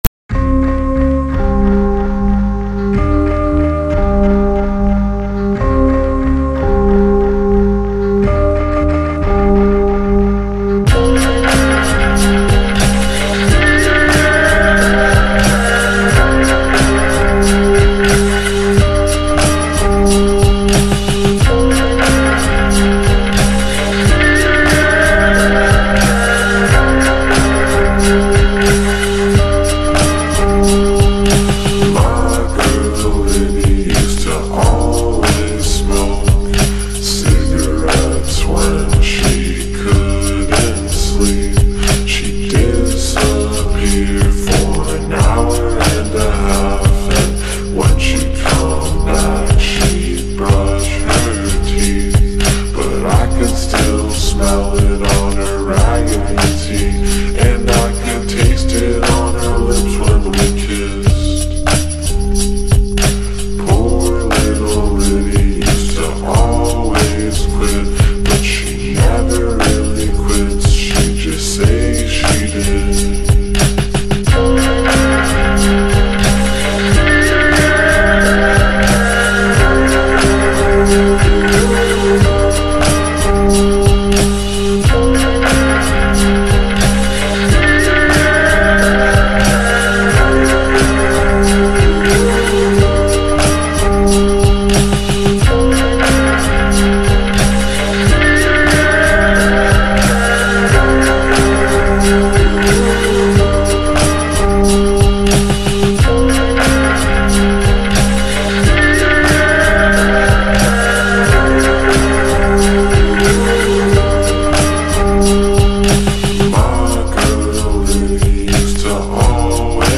نسخه Slowed و کند شده
غمگین